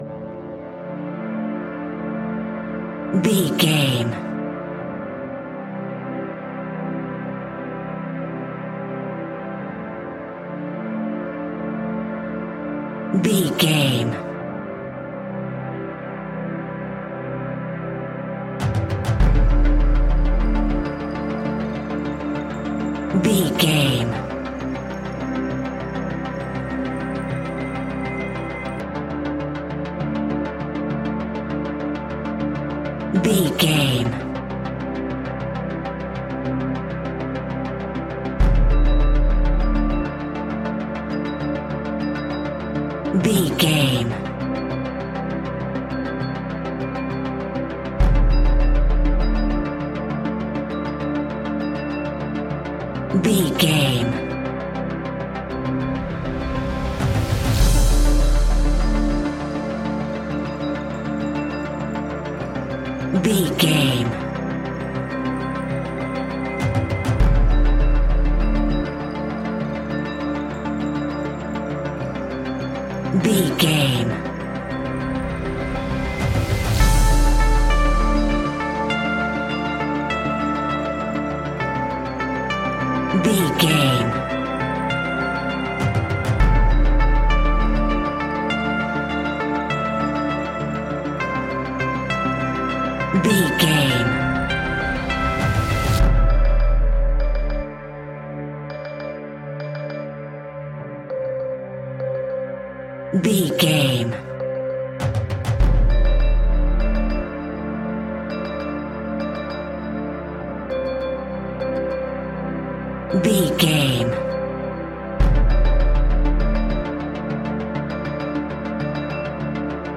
royalty free music
Aeolian/Minor
D
tension
ominous
dark
dramatic
haunting
eerie
drums
percussion
strings
synthesiser
instrumentals
horror music